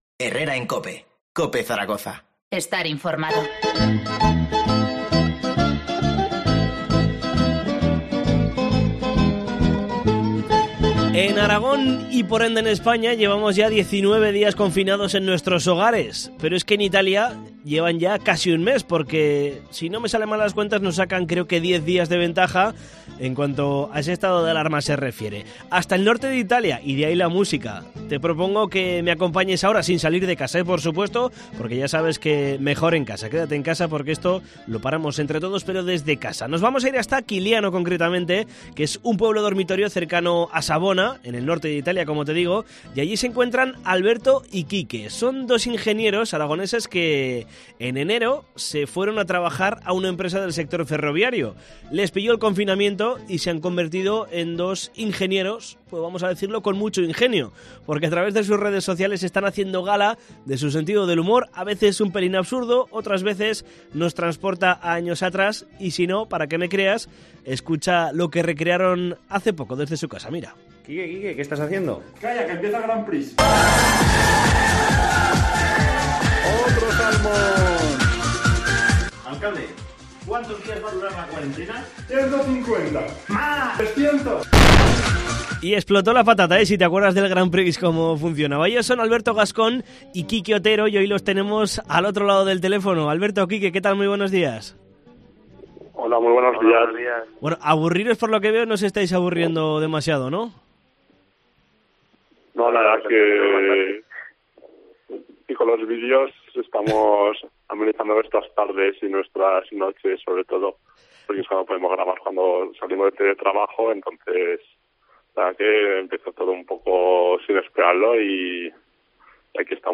En Cope Zaragoza nos han explicado cómo lo están viviendo.